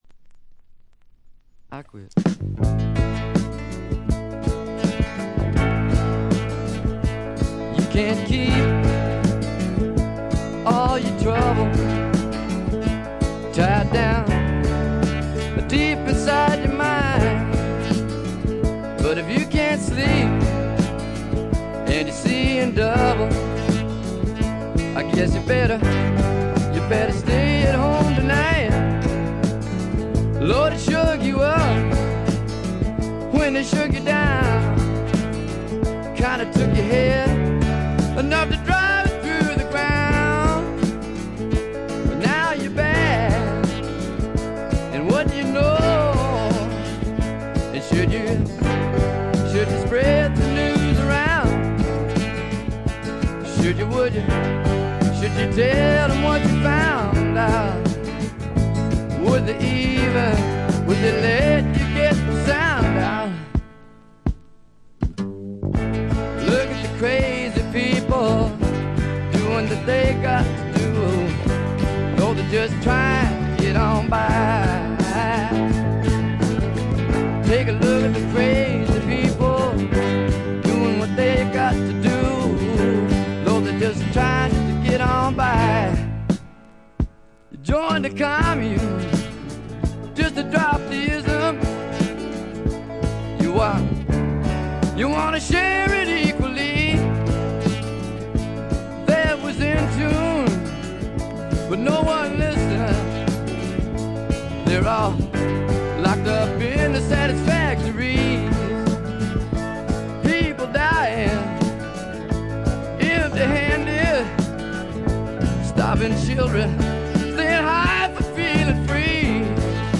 中身はヴォーカルも演奏も生々しくラフなサウンドがみっちり詰まっている充実作で、名盤と呼んでよいでしょう。
試聴曲は現品からの取り込み音源です。